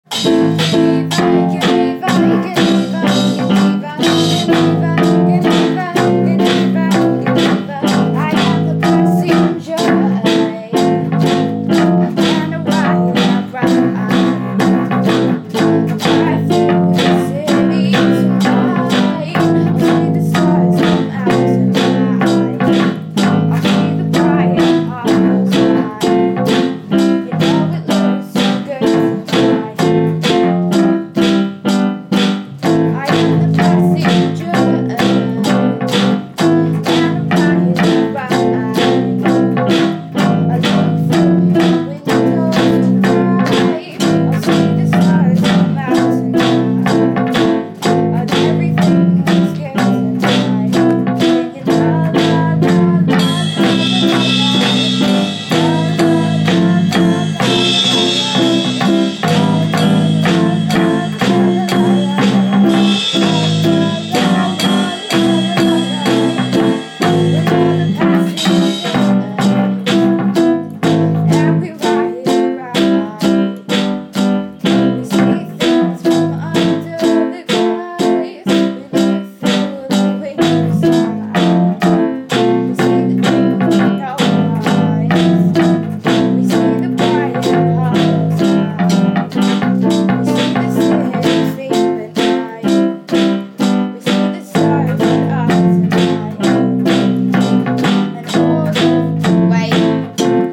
drummer
bass guitar
piano